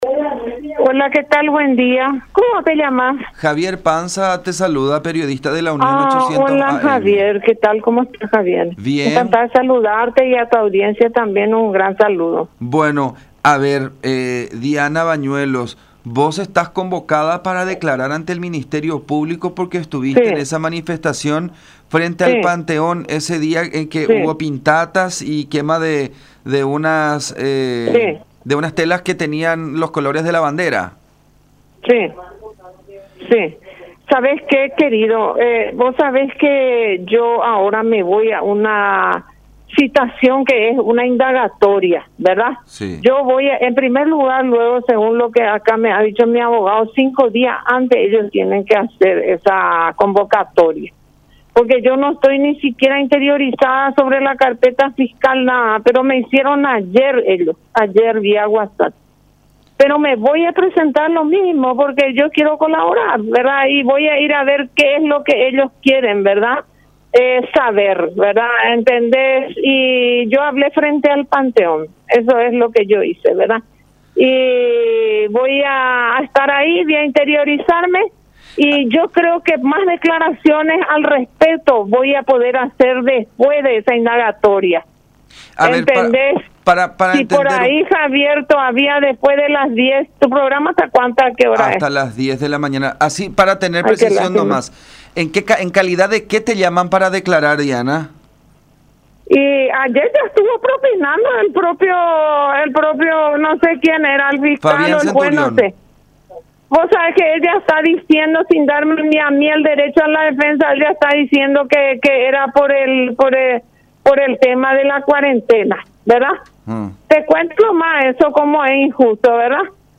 en diálogo con La Unión